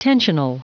Prononciation du mot tensional en anglais (fichier audio)
Prononciation du mot : tensional